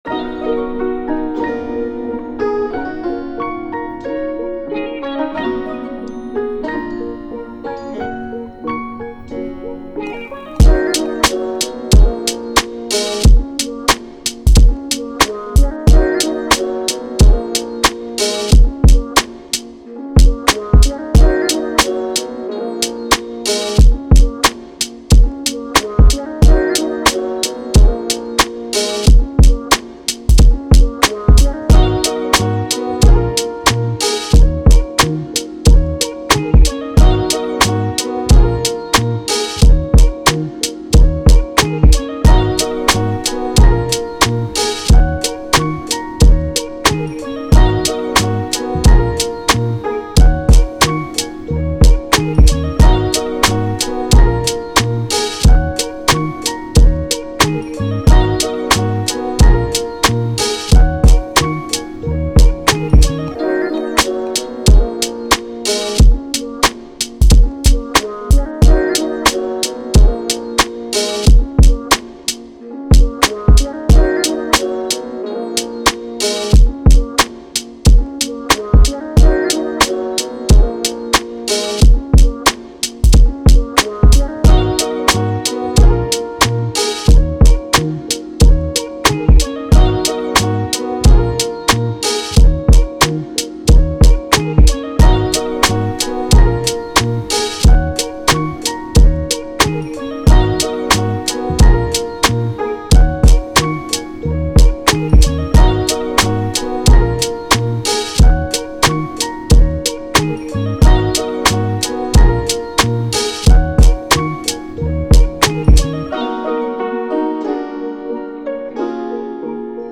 Hip Hop, R&B
F# Major